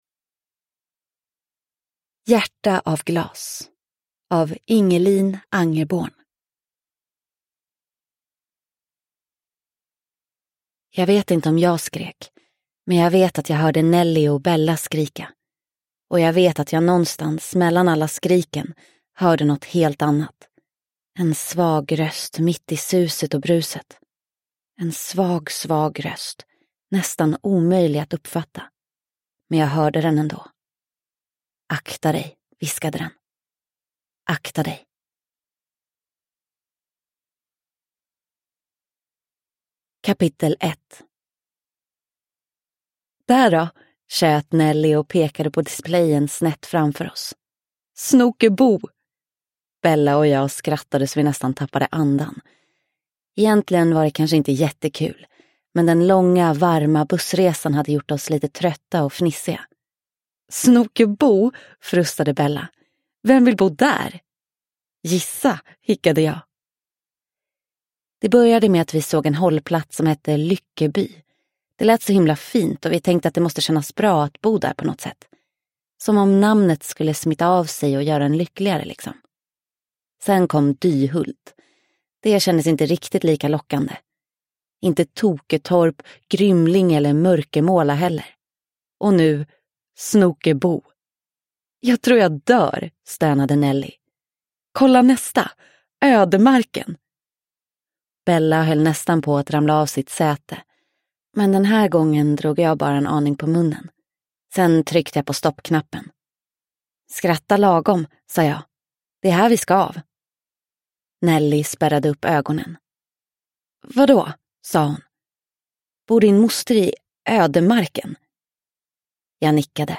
Hjärta av glas – Ljudbok – Laddas ner